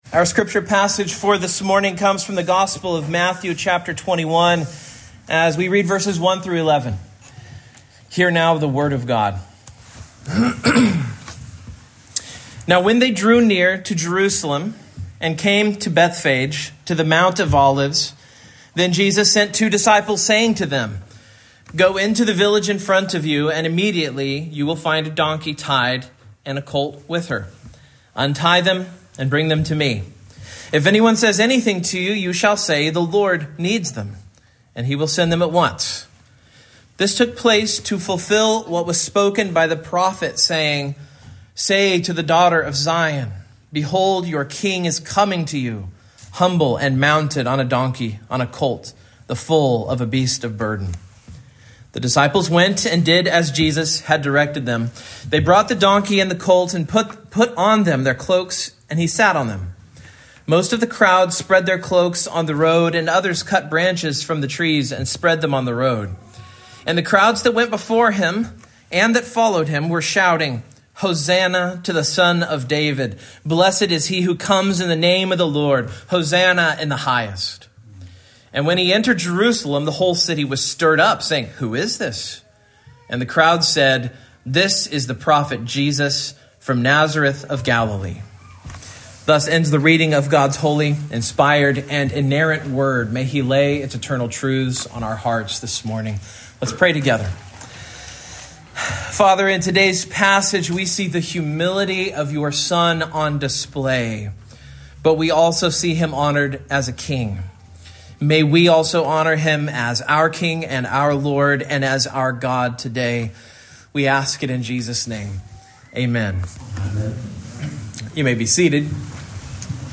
Matthew 21:1-11 Service Type: Morning Main Point